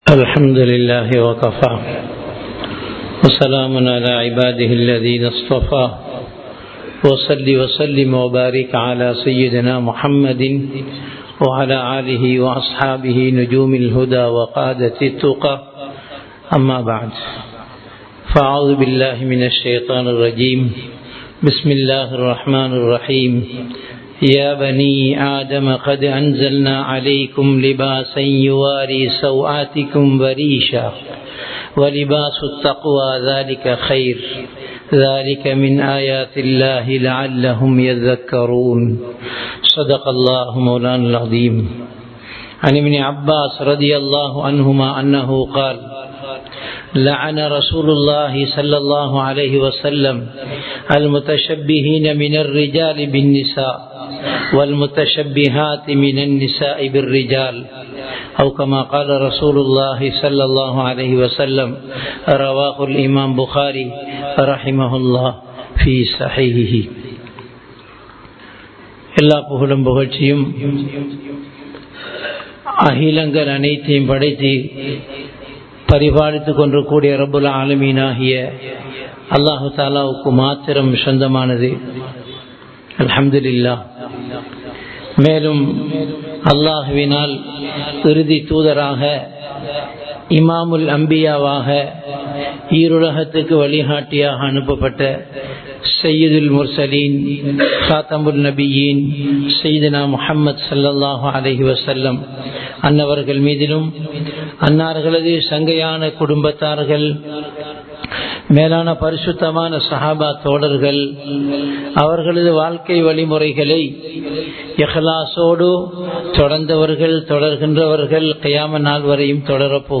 இஸ்லாம் தடுத்தவைகள் | Audio Bayans | All Ceylon Muslim Youth Community | Addalaichenai
Muhiyadeen Jumua Masjith